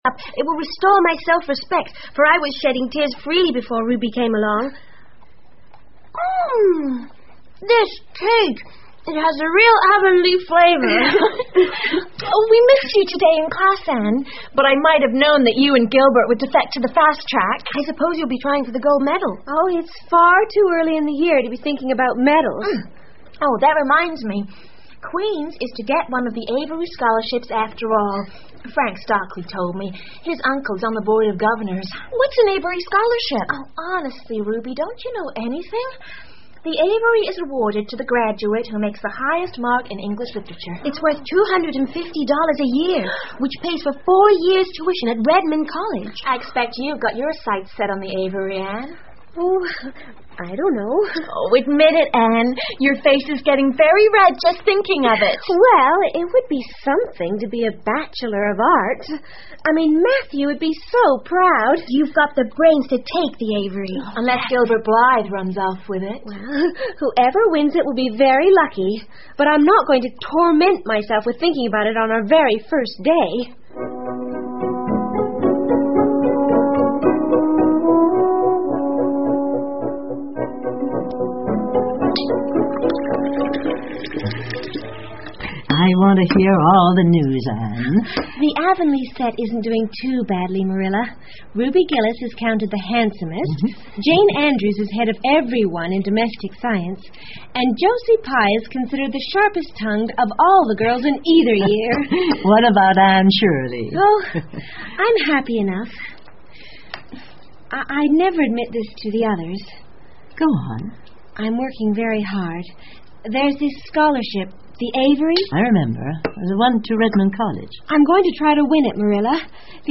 绿山墙的安妮 Anne of Green Gables 儿童广播剧 25 听力文件下载—在线英语听力室